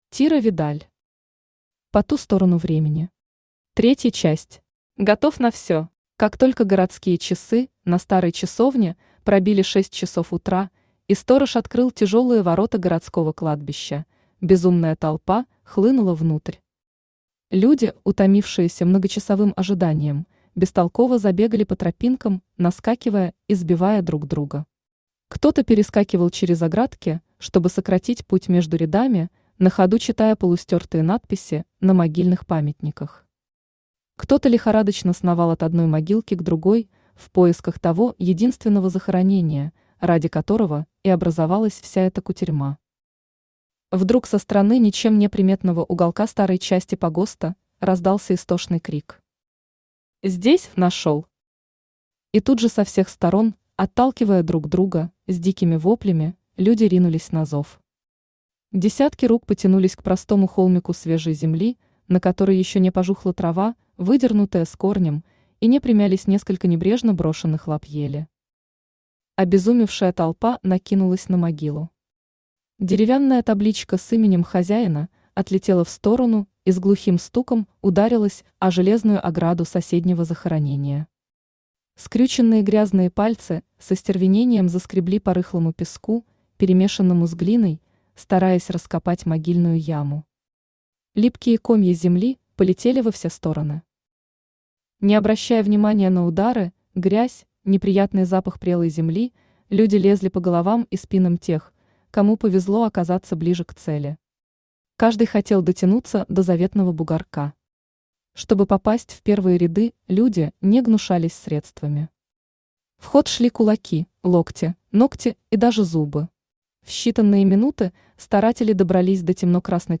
Аудиокнига Поту сторону времени. 3 часть. Готов на все | Библиотека аудиокниг
Готов на все Автор Тира Видаль Читает аудиокнигу Авточтец ЛитРес.